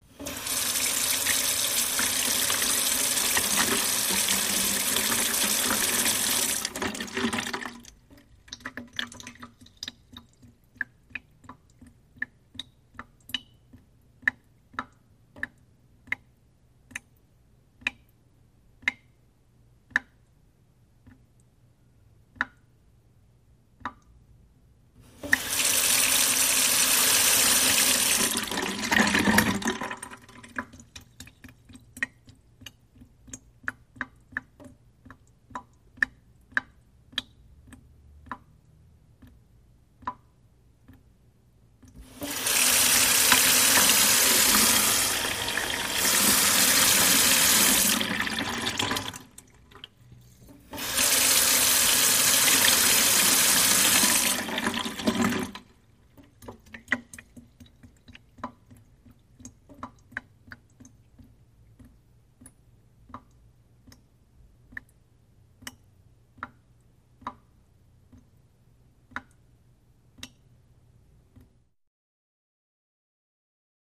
Water, Run & Drops; Water Runs In Metal Container And Drips Big And Little Drops, Alternating Between Running And Dripping ( Kitchen Sink On / Off )